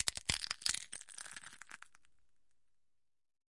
食品 " 薯片 脆片 广
描述：我手中的碎片，麦克风设置，使声音非常立体。 用Tascam DR40录制。
标签： 芯片 挤压 压薄 junkfood 粉碎 松脆 芯片 嘎吱作响 糊状 紧缩 嘎吱嘎吱
声道立体声